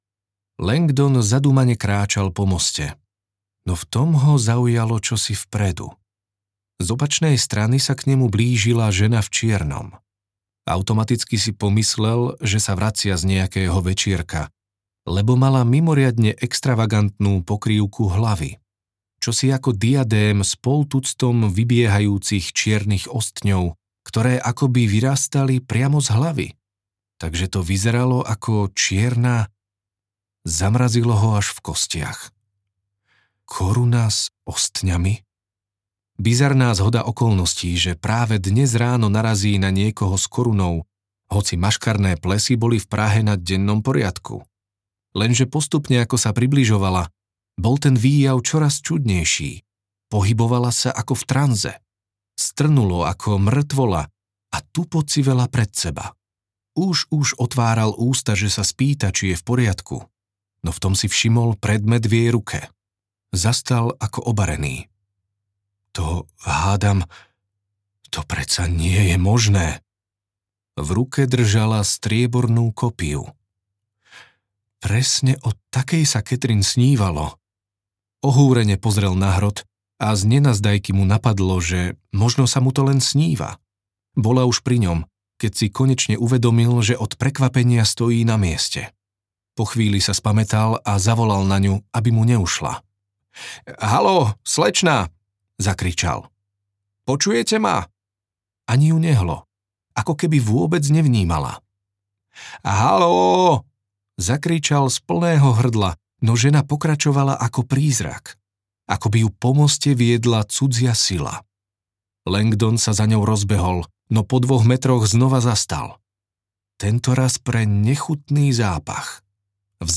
Posledné tajomstvo audiokniha
Ukázka z knihy
posledne-tajomstvo-audiokniha